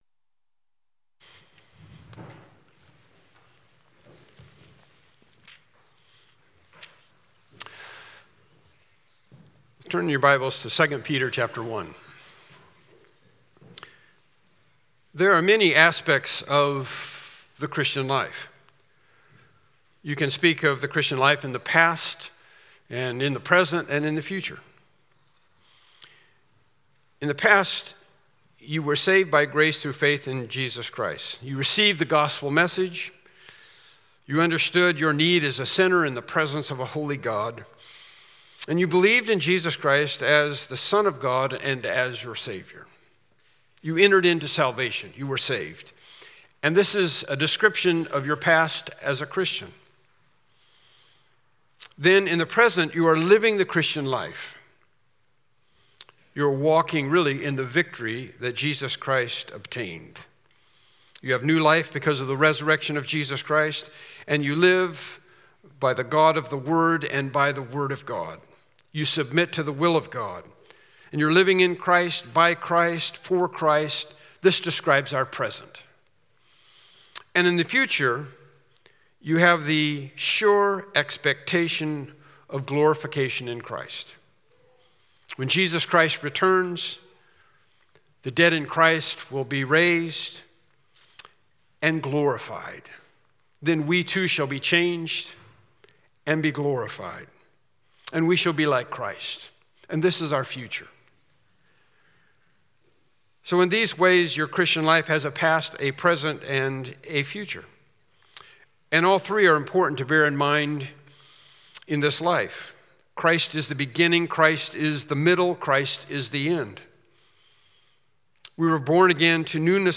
2 Peter 1:1-3 Service Type: Morning Worship Service « The Duty of the Church